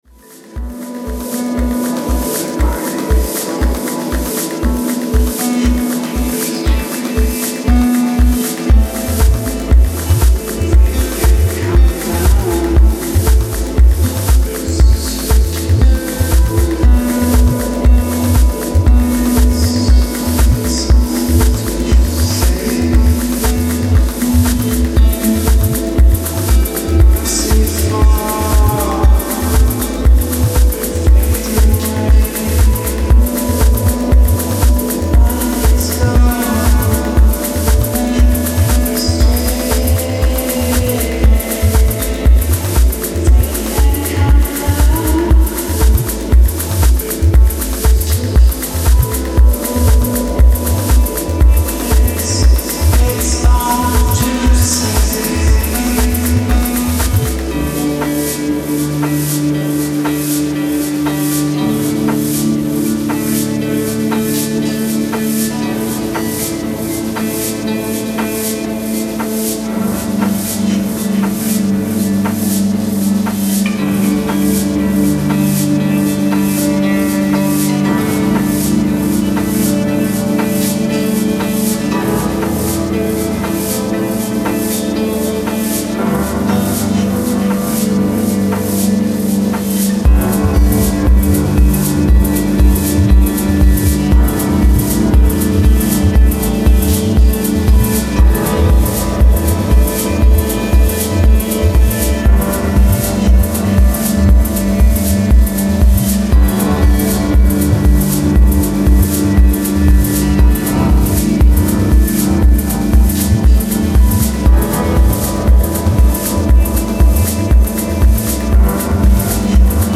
Style: Techno / Electronica